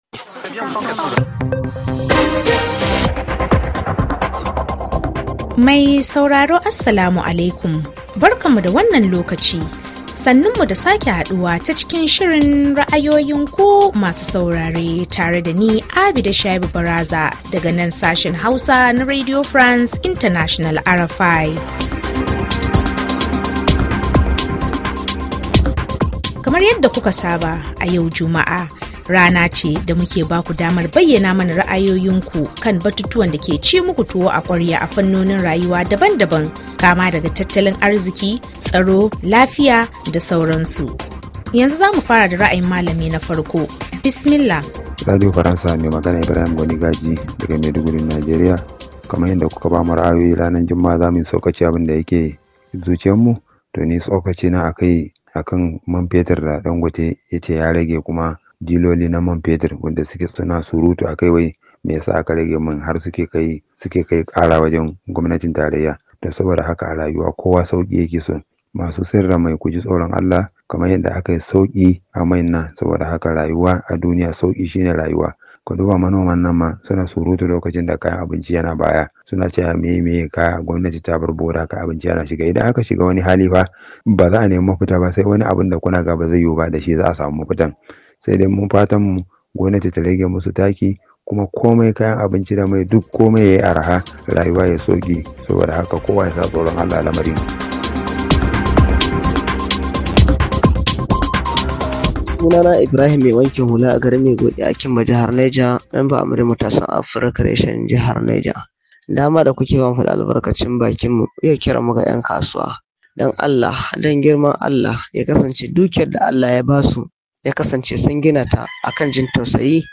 Shiri ne na musamman, da kan tattaunawa da bangarori daban-daban na Al’umma da ke sauraren Shirye shiryen RFI Hausa daga kowane kusurwa na fadin duniya, kan muhimman batutuwa, da suka shafi siyasa Tattalin’ariziki, al’adu da dai sauransu…